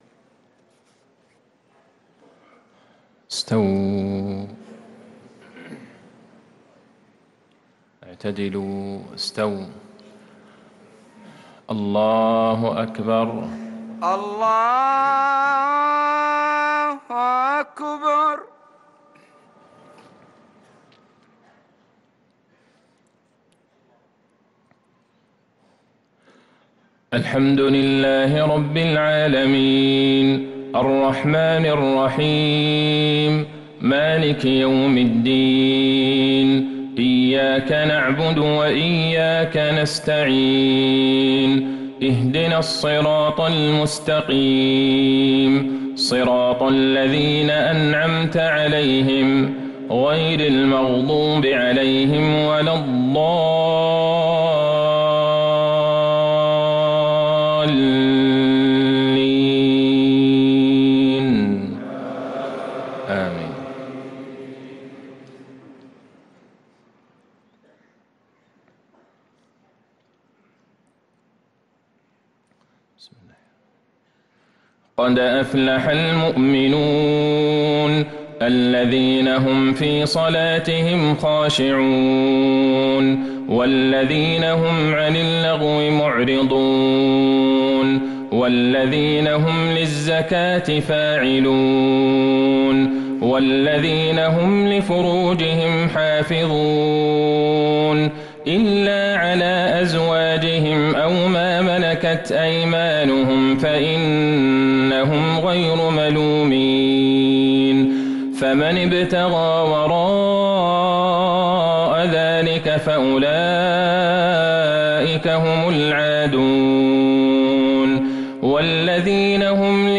صلاة المغرب للقارئ عبدالله البعيجان 30 ربيع الأول 1445 هـ
تِلَاوَات الْحَرَمَيْن .